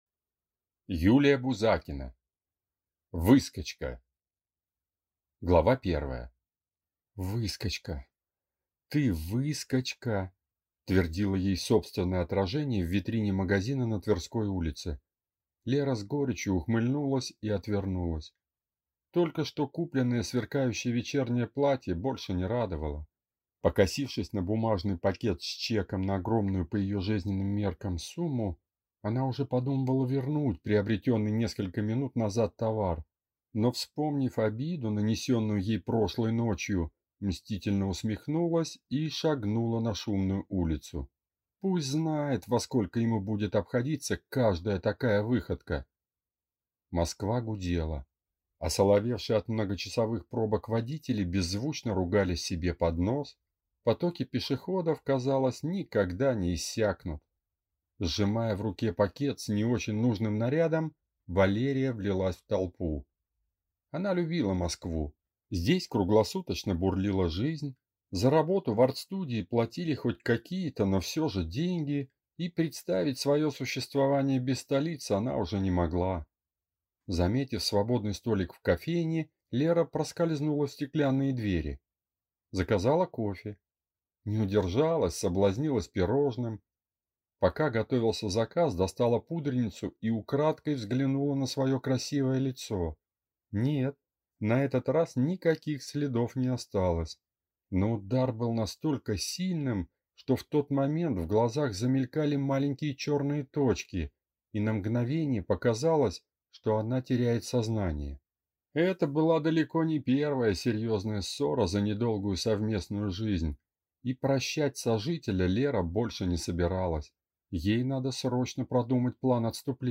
Аудиокнига Выскочка | Библиотека аудиокниг